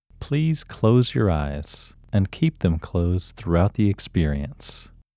WHOLENESS English Male 1
WHOLENESS-English-Male-1.mp3